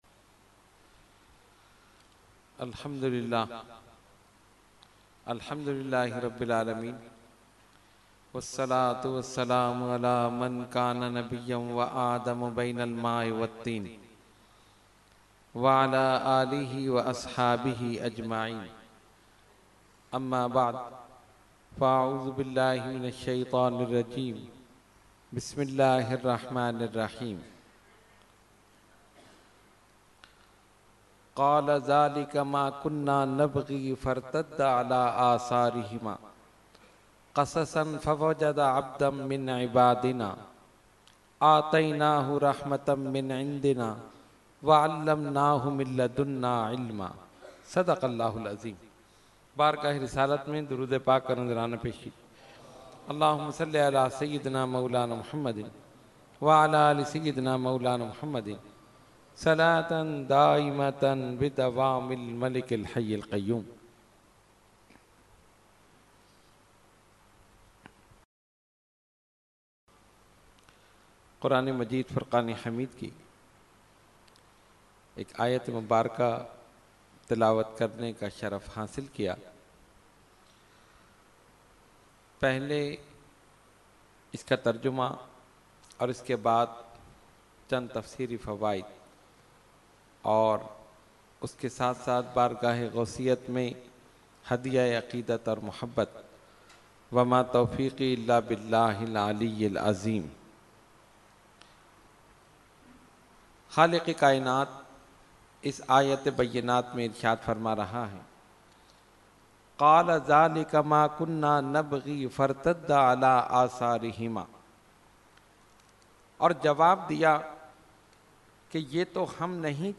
Mehfil e 11veen Shareef held 11 December 2020 at Dargah Alia Ashrafia Ashrafabad Firdous Colony Gulbahar Karachi.
Category : Speech | Language : UrduEvent : 11veen Shareef 2020